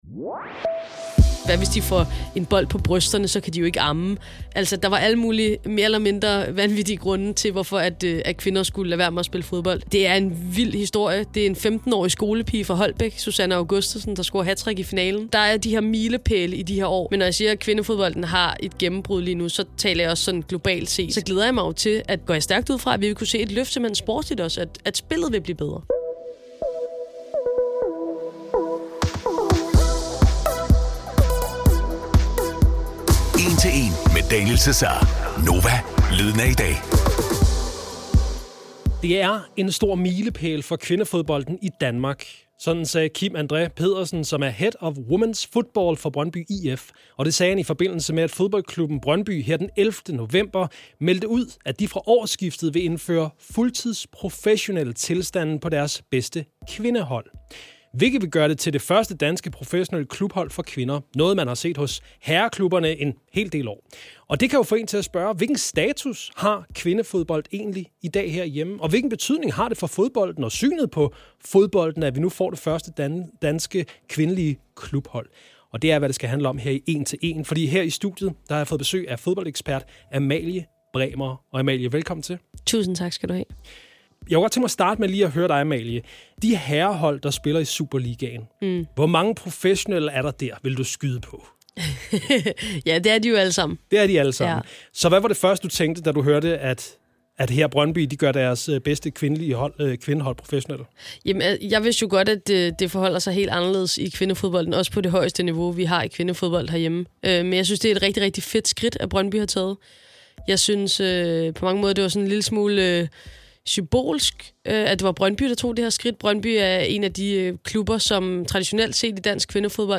gæster studiet til en snak om dansk kvindefodbold anno 2024.